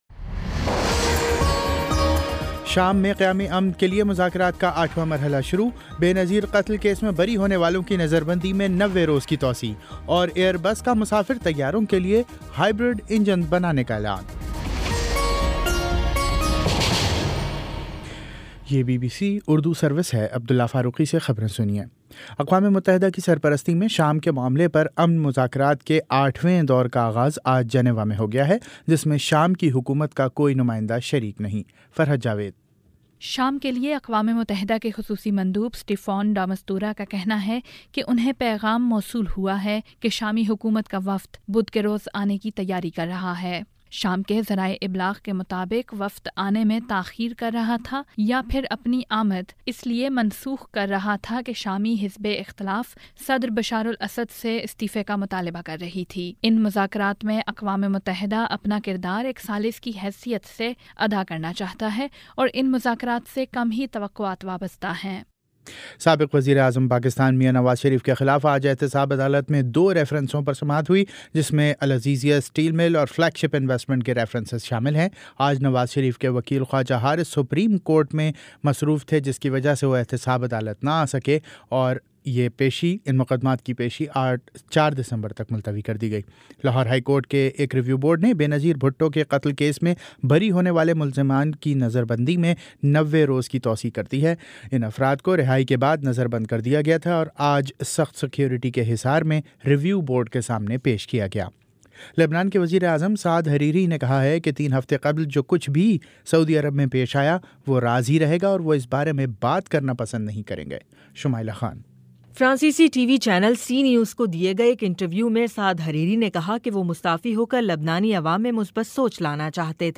نومبر28 : شام چھ بجے کا نیوز بُلیٹن